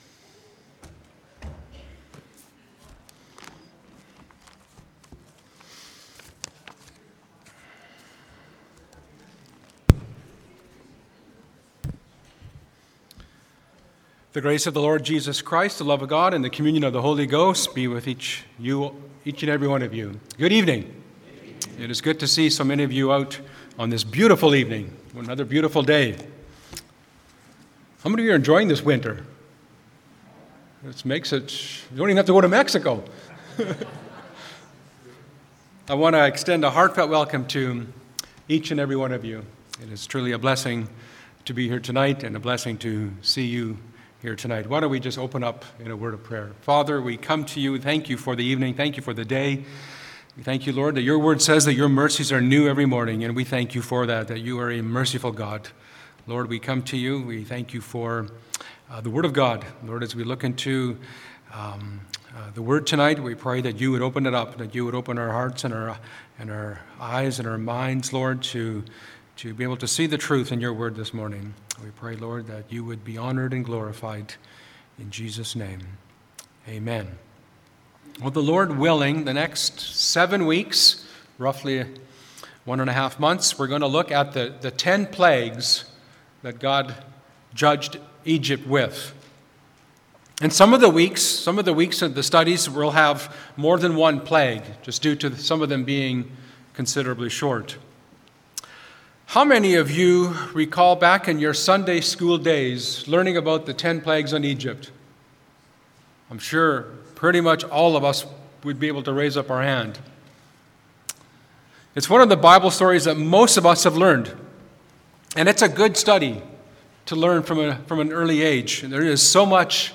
Church Bible Study – The Ten Plagues of Egypt